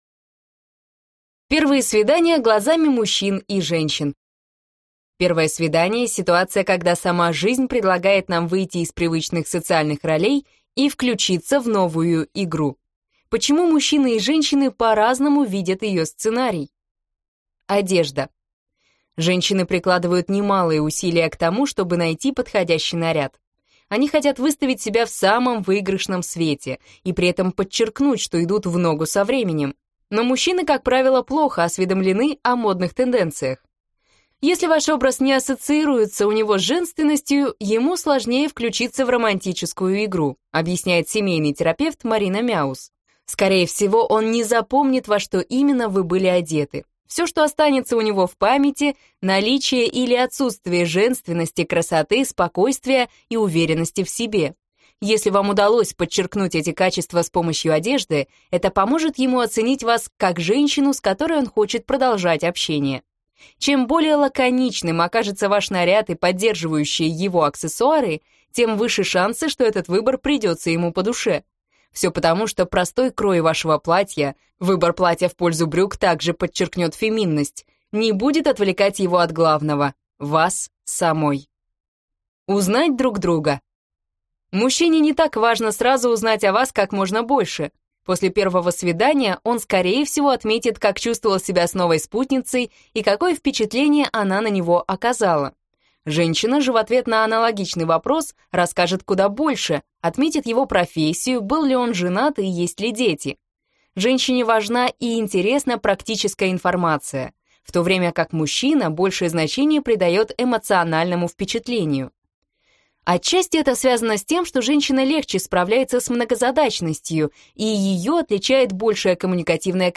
Аудиокнига Как испортить первое свидание: знакомство, разговоры, секс | Библиотека аудиокниг